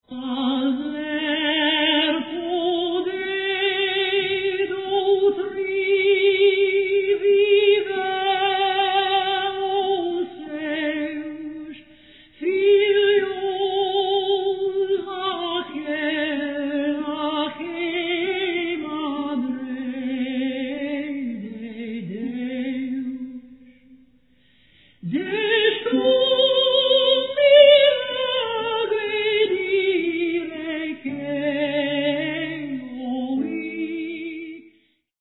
countertenor
lute